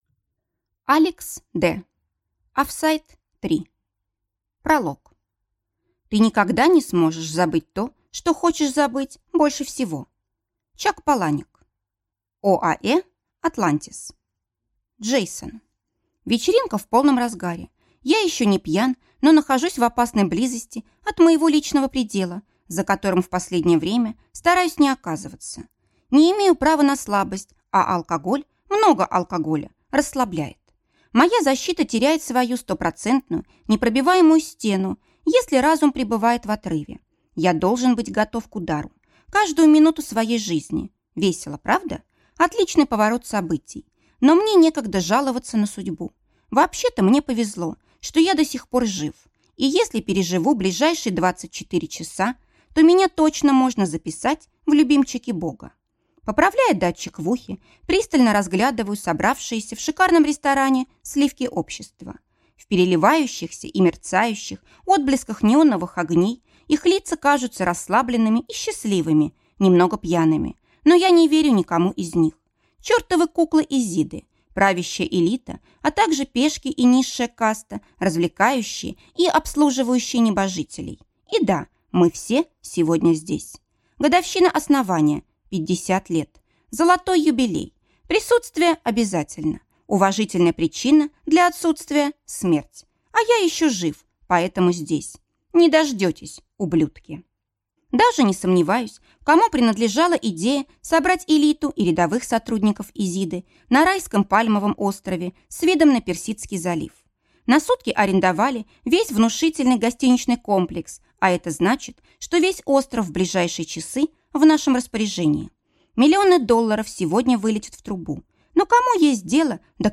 Аудиокнига Офсайд 3 | Библиотека аудиокниг